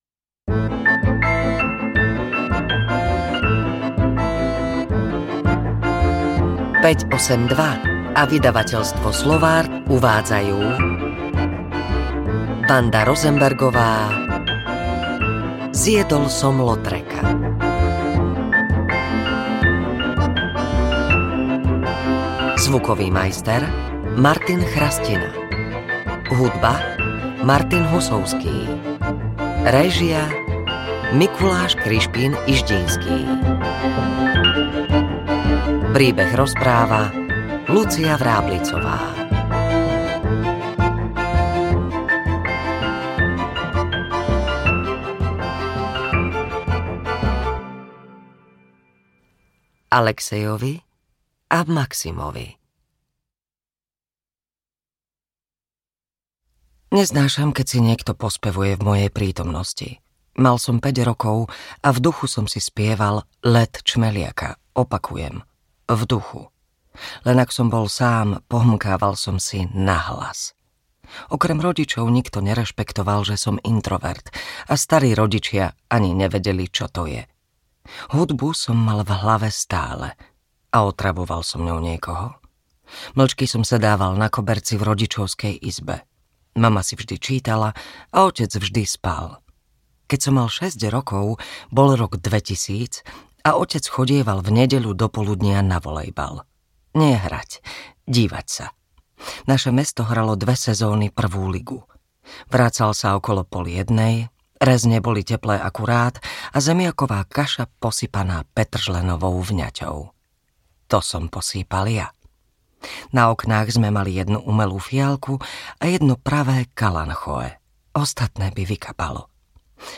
Zjedol som Lautreca audiokniha
Ukázka z knihy